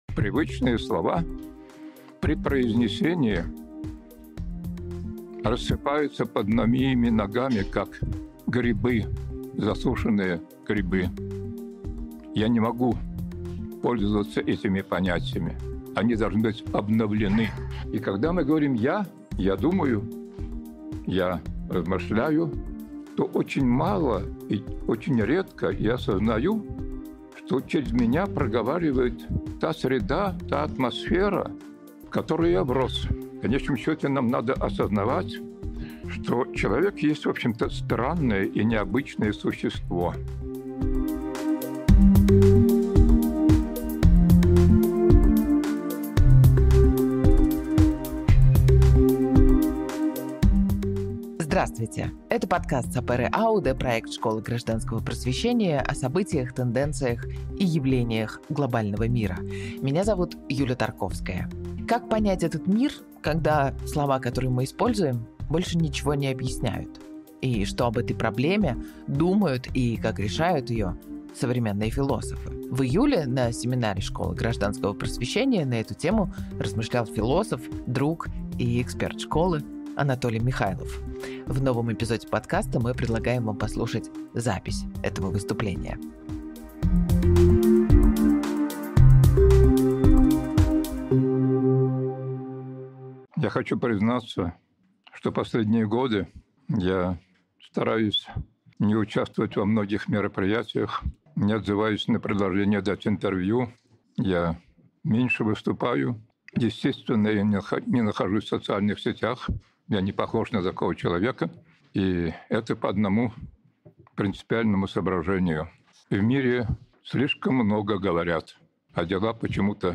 Сегодняшний эпизод — запись выступления